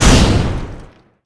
bipa_explo.wav